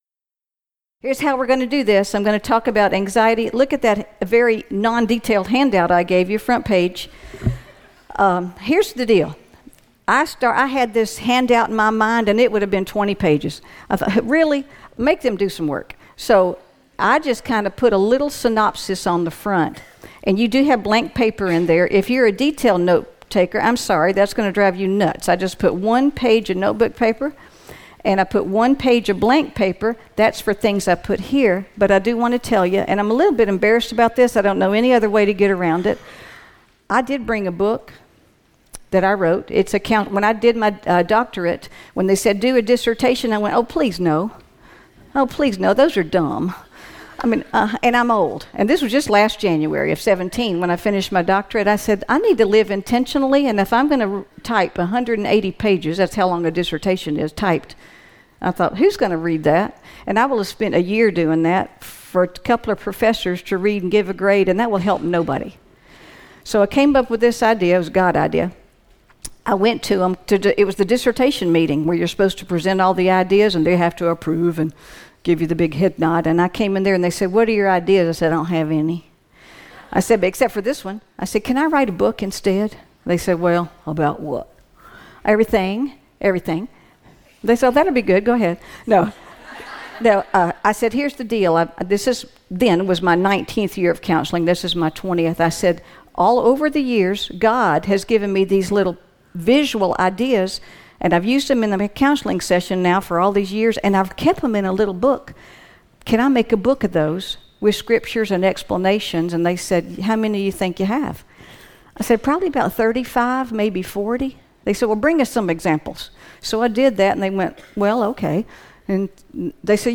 2018 Emmaus Church Women's Conference